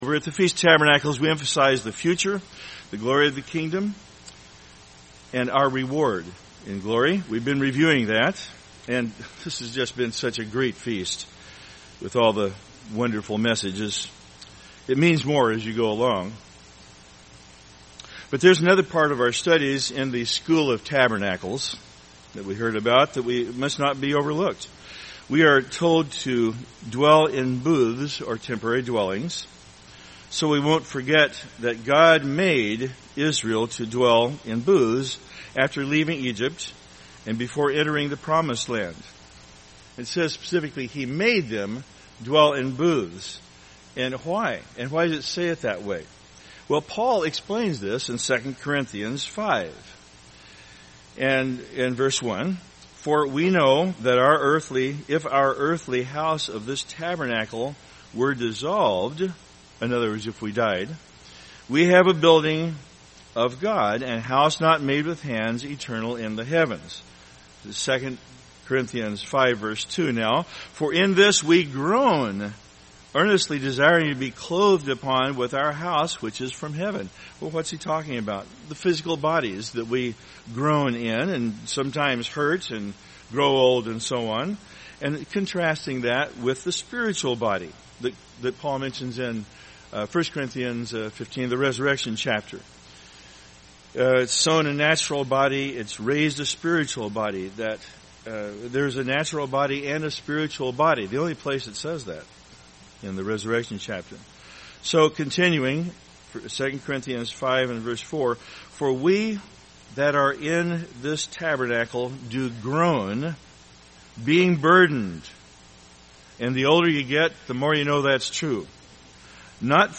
This sermon was given at the Wisconsin Dells, Wisconsin 2014 Feast site.